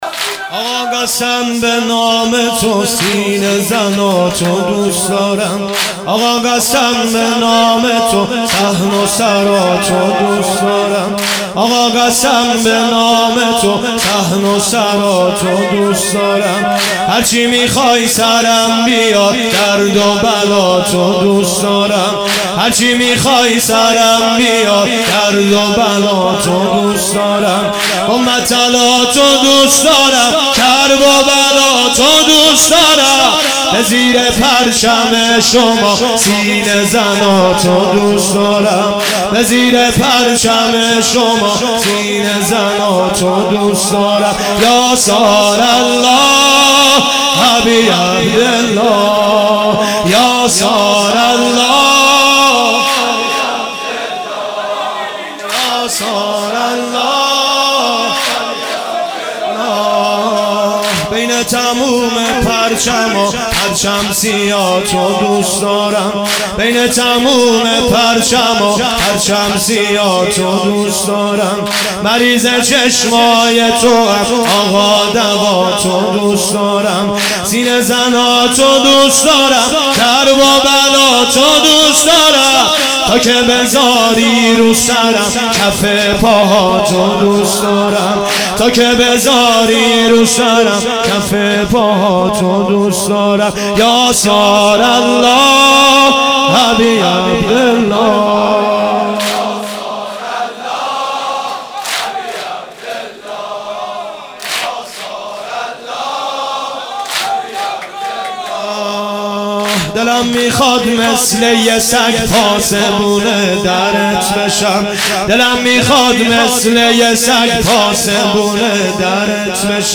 مدح